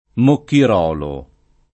[ mokkir 0 lo ]